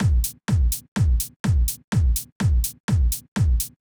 Drumloop 125bpm 02-C.wav